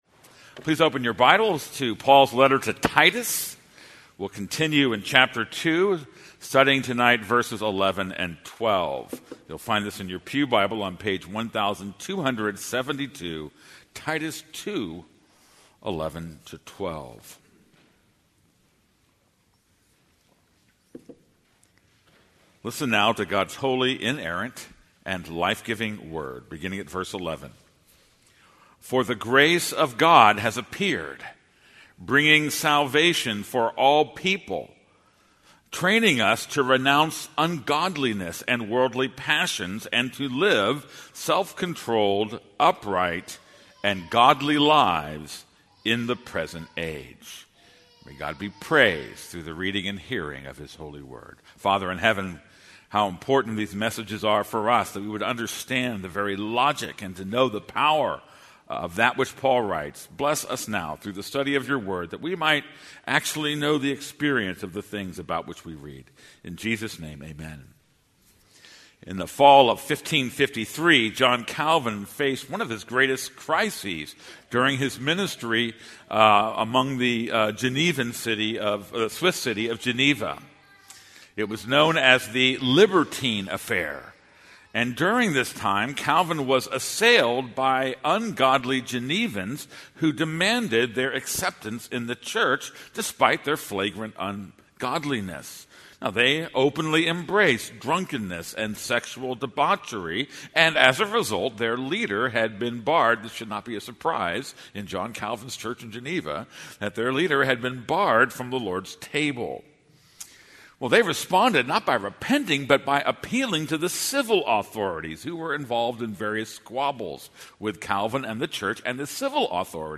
This is a sermon on Titus 2:11-12.